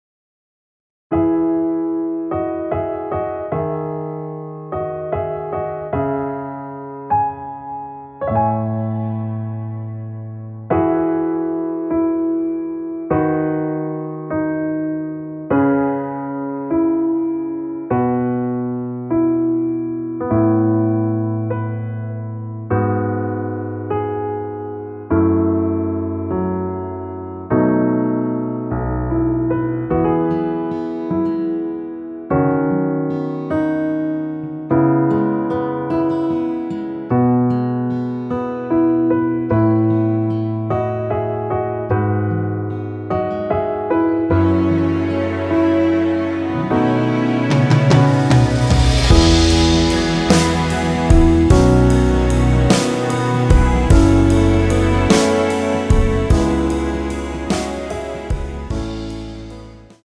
끝없는 밤을 걷자후에 2절의 나의 모든 날들을로 진행이 됩니다.
◈ 곡명 옆 (-1)은 반음 내림, (+1)은 반음 올림 입니다.
앞부분30초, 뒷부분30초씩 편집해서 올려 드리고 있습니다.
중간에 음이 끈어지고 다시 나오는 이유는